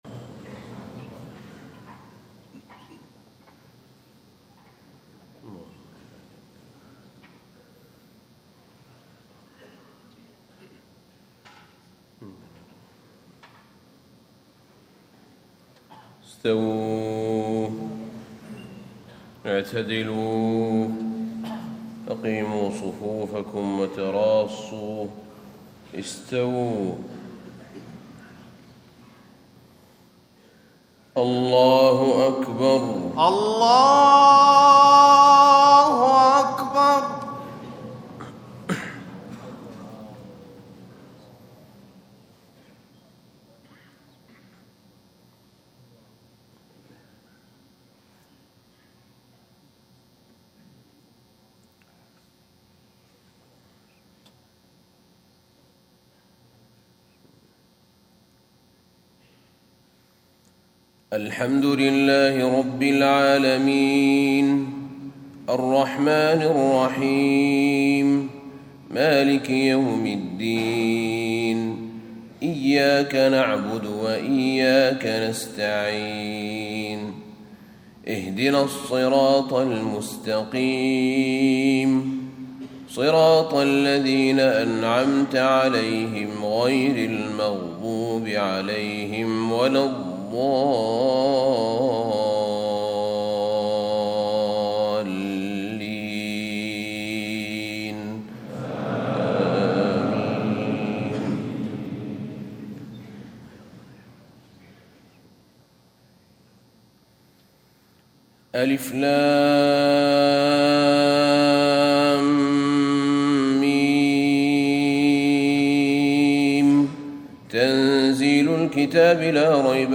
صلاة الفجر 29 صفر 1437هـ سورتي السجدة و الإنسان > 1437 🕌 > الفروض - تلاوات الحرمين